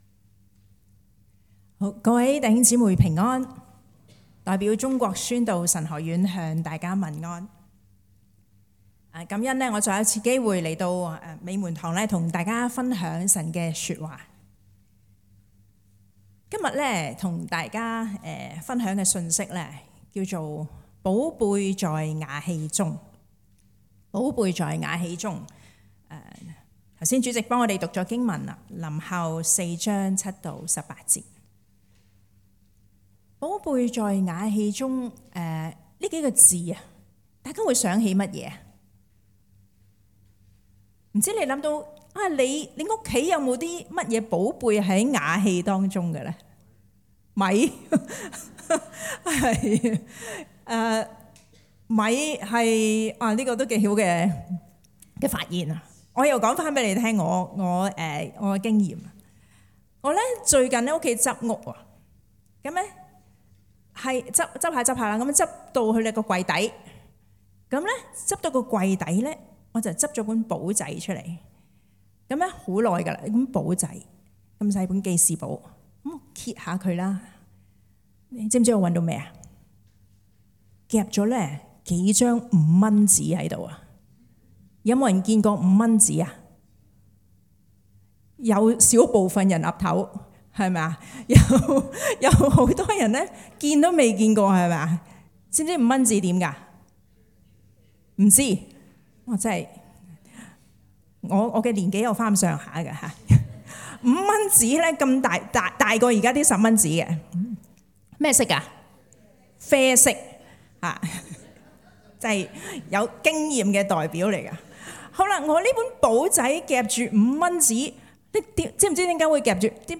講道類別 : 主日崇拜 經文章節 : 哥林多後書 4 : 7 - 18